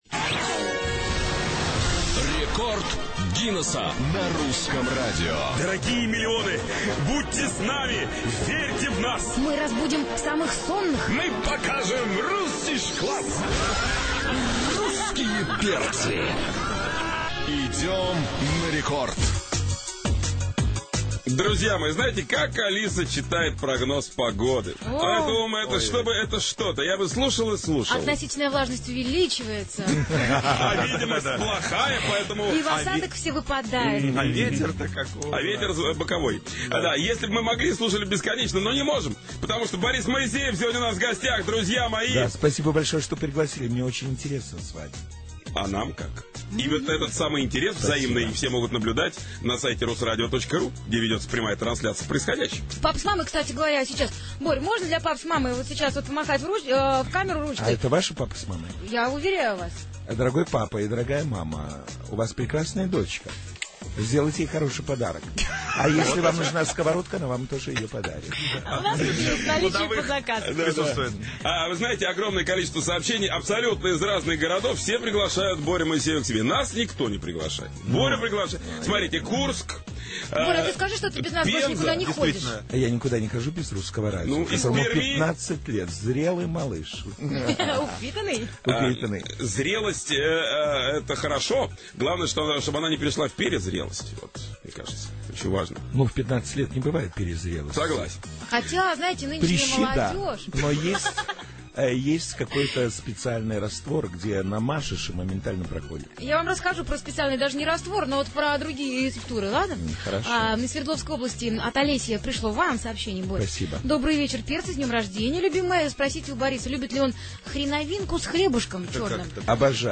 Рекорд Гиннеcса на "Русском радио" vol.2. Запись эфира.
Прошло более 12 часов с начала беспрерывного шоу "Русских перцев". На этот раз в гостях был Борис Моисеев.